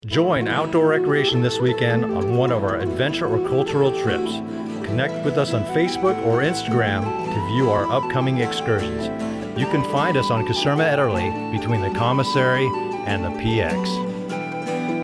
a brief Radio commercial for Outdoor recreation showcasing some of the services they offer.
commercialspot